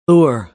us_phonetics_sound_pure_2023feb.mp3